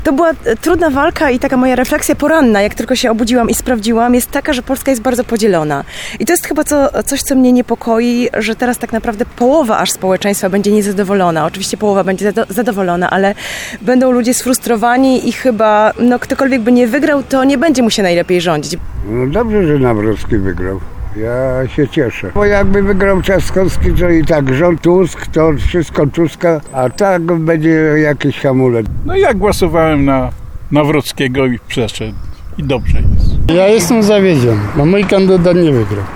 Większość tarnowian, z którymi rozmawialiśmy jest zadowolona z wyników wyborów prezydenckich, które wygrał Karol Nawrocki.
2sonda-wybory.mp3